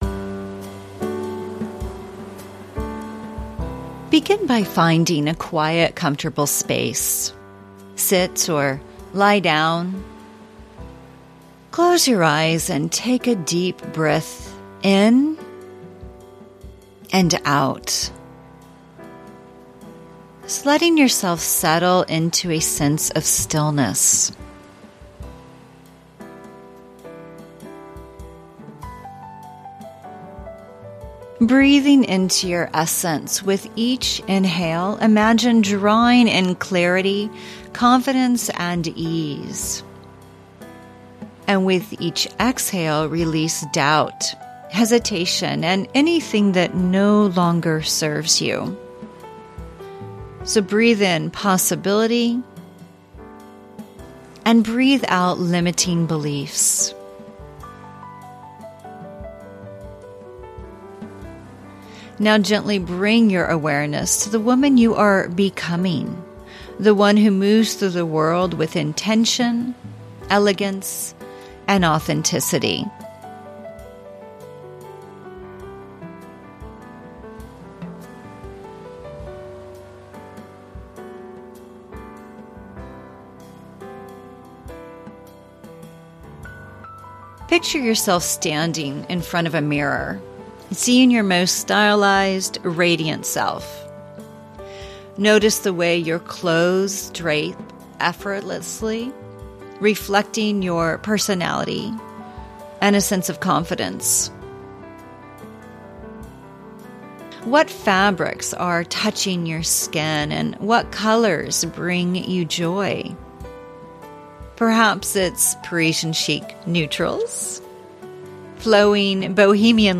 Private Guided Meditation